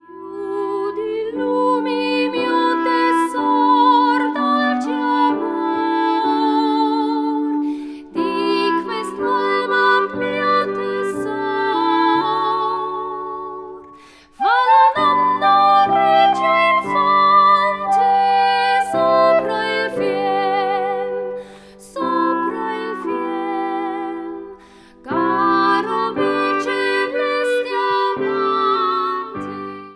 für Sopran/Mezzosopran, Klarinette und Klavier
Beschreibung:Klassik; Kammermusik; Gesang; Weihnachten
Besetzung:Sopran/Mezzosopran, Klarinette, Klavier